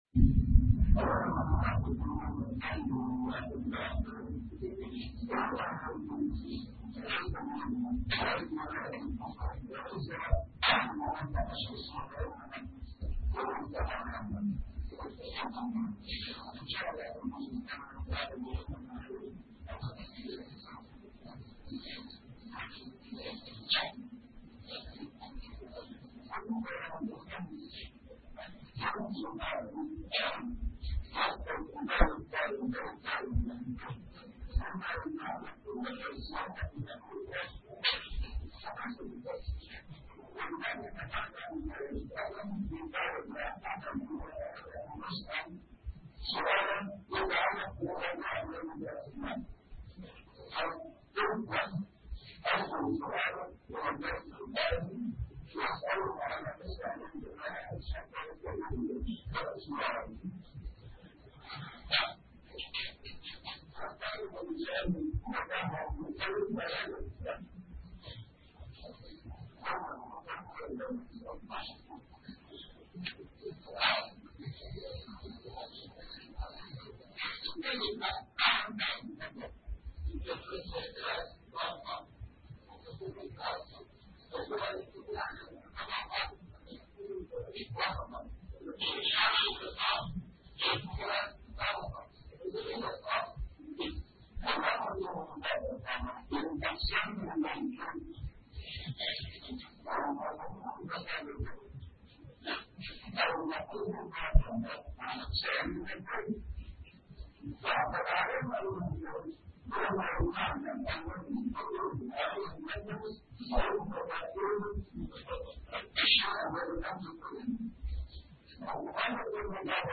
A MARTYR SCHOLAR: IMAM MUHAMMAD SAEED RAMADAN AL-BOUTI - الدروس العلمية - مباحث الكتاب والسنة - الدرس السابع: في المنطوق والمفهوم وقواعد الدلالة في كل منهما [2]